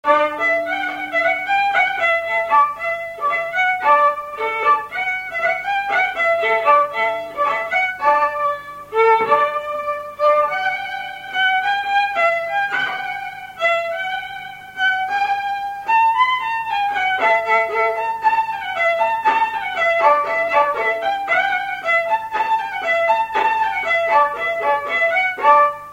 Polka manège
Résumé instrumental
Pièce musicale inédite